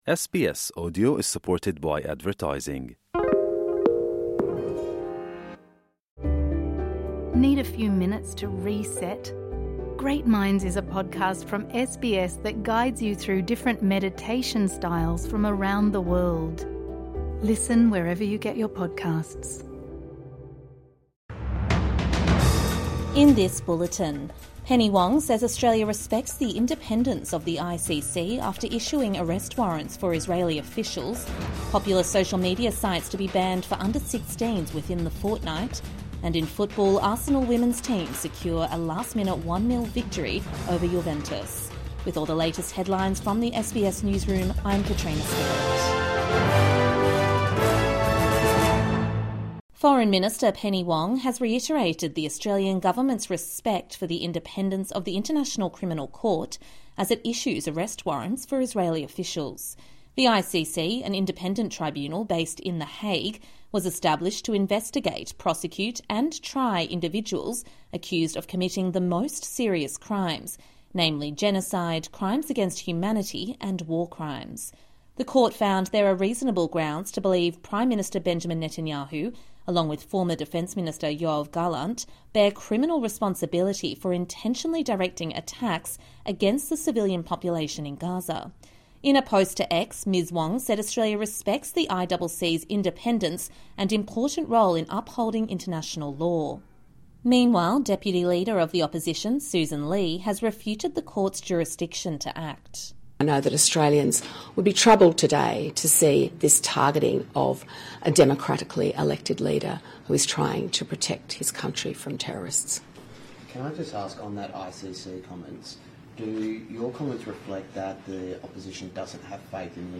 A bulletin of the day’s top stories from SBS News. Get a quick rundown of the latest headlines from Australia and the world, with fresh updates each morning, lunchtime and evening.